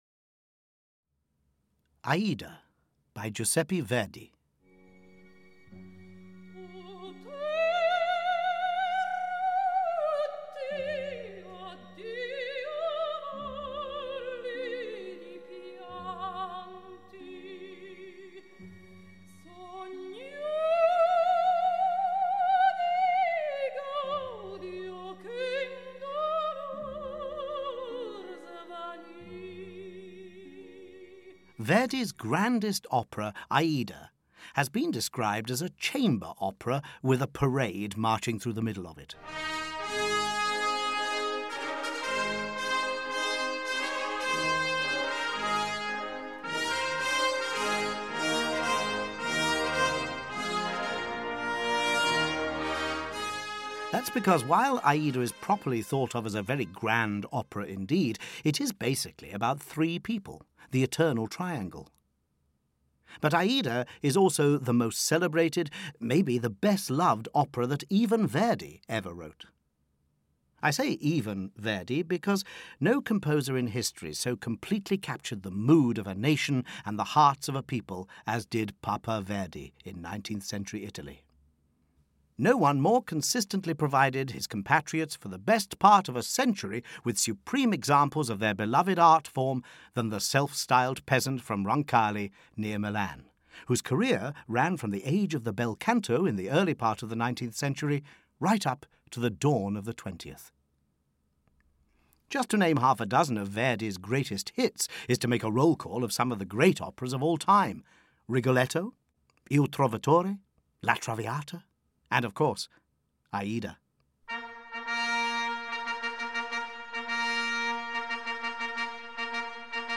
Opera Explained – Aida (EN) audiokniha
Ukázka z knihy